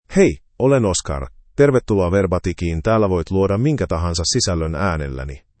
Oscar — Male Finnish AI voice
Oscar is a male AI voice for Finnish (Finland).
Voice sample
Listen to Oscar's male Finnish voice.
Oscar delivers clear pronunciation with authentic Finland Finnish intonation, making your content sound professionally produced.